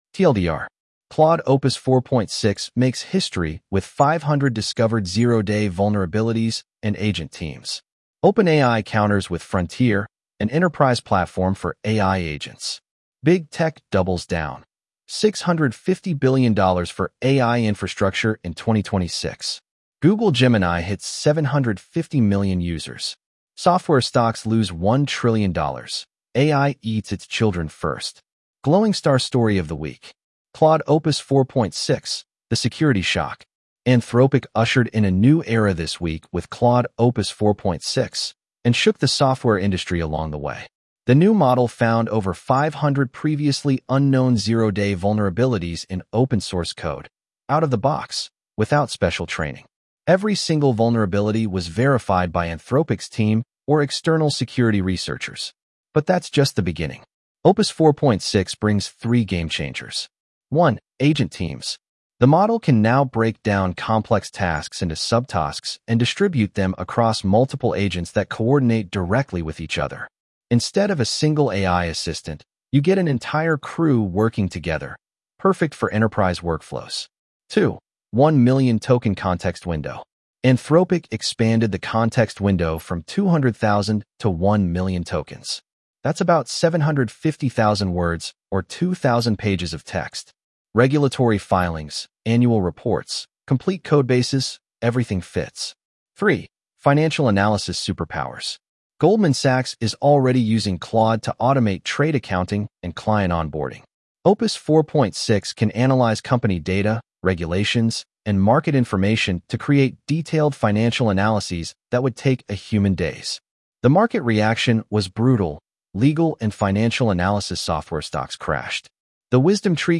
Read aloud with edge-tts (en-US-AndrewNeural)